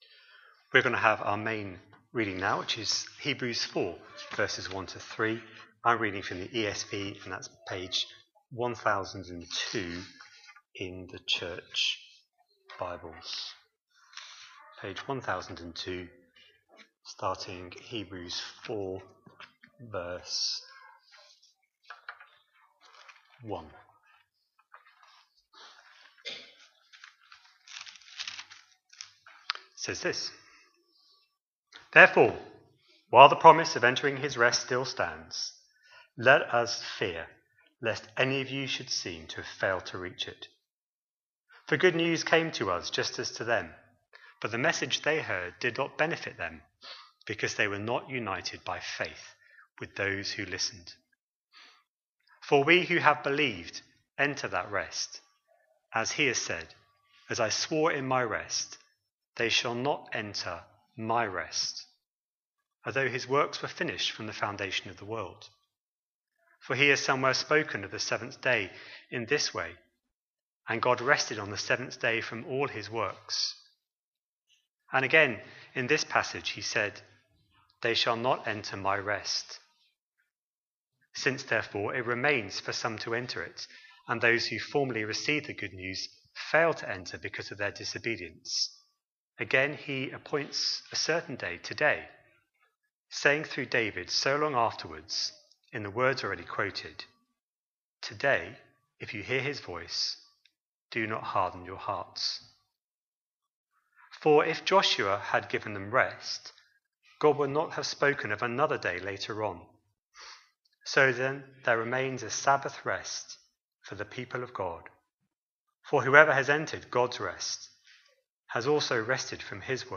A sermon preached on 10th November, 2024, as part of our Hebrews 24/25 series.